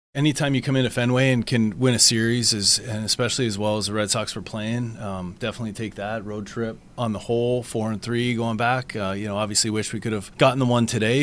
Manager Donnie Kelly was pleased with the trip to Boston and the entire road trip.